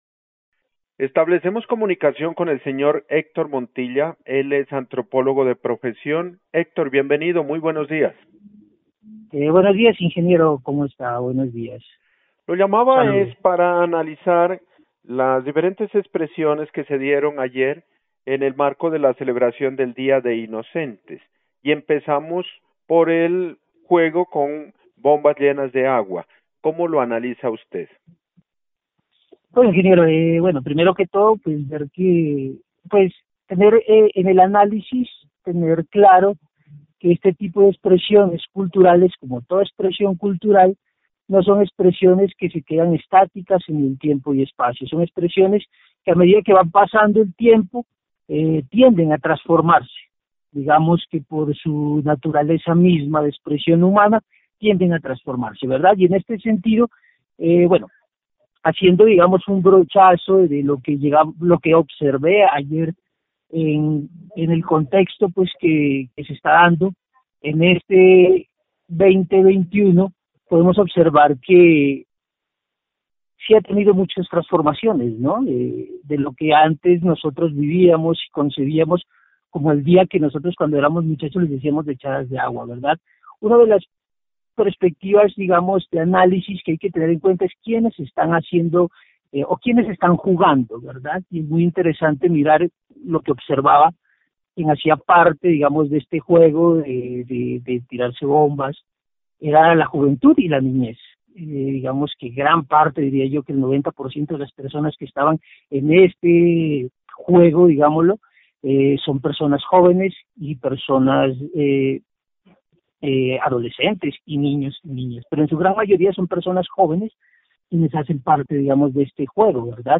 En la entrevista